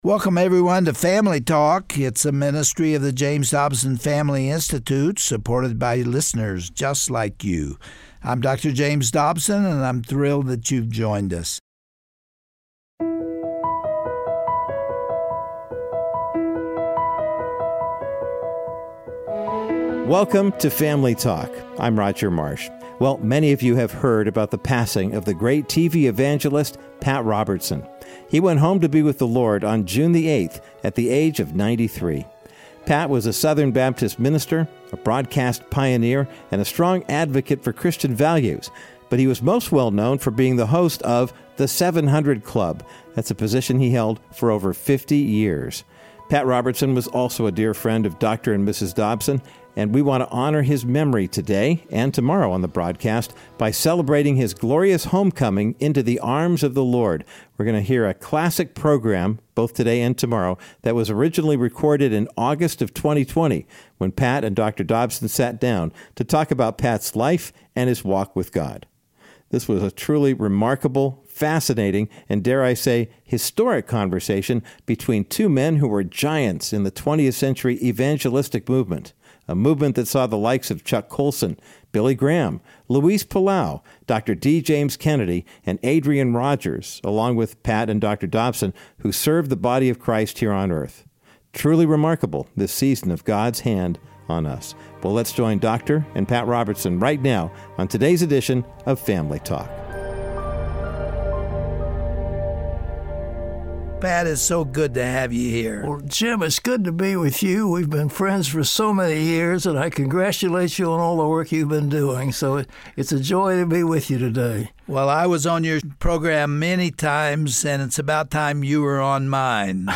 Find out how deeply this statement embedded itself in the life of the late religious broadcast icon, Pat Robertson, and how it moved him to follow Jesus and further God’s Kingdom through media and politics. On today’s edition of Family Talk, Dr. James Dobson discusses with Pat the journey God laid out for him since 1960, and how the Lord had showed Himself faithful.